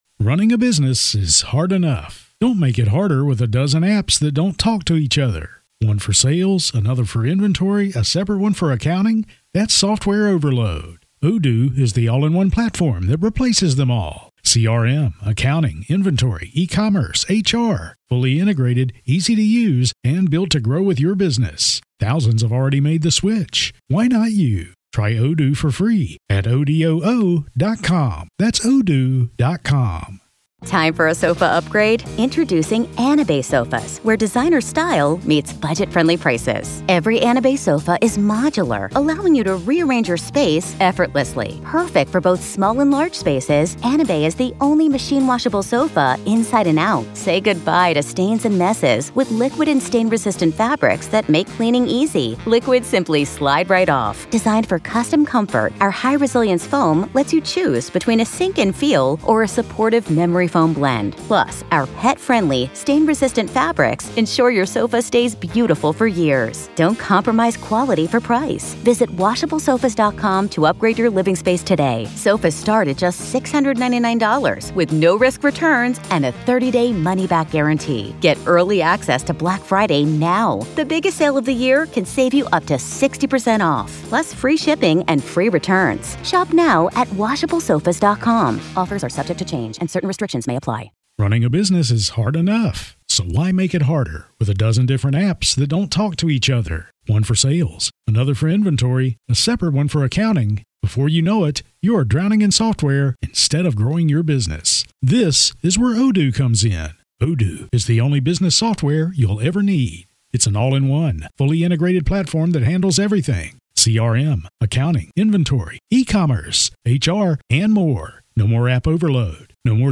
True Crime Today | Daily True Crime News & Interviews
Each episode navigates through multiple stories, illuminating their details with factual reporting, expert commentary, and engaging conversation.